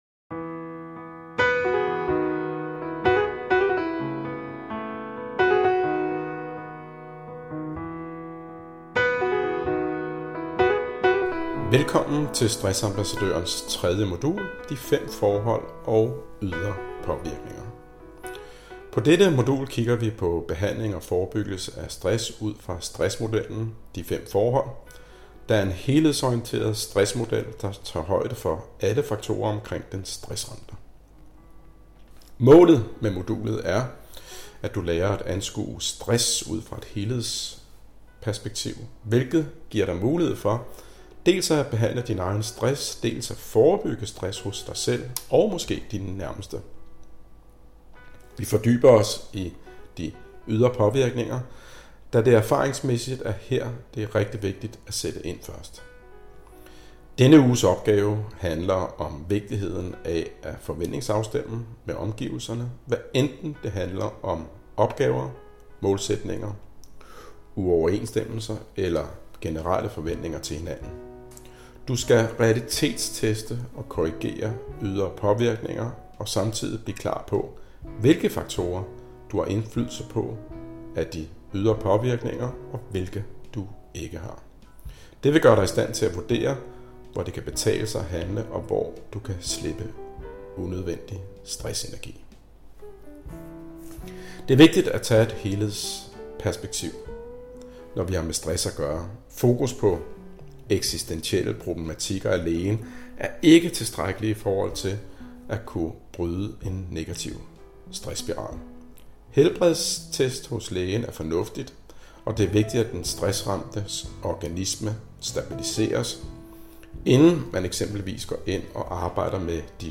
Artikel-Indlaest-Modul-3-Artikel-Indlaest.mp3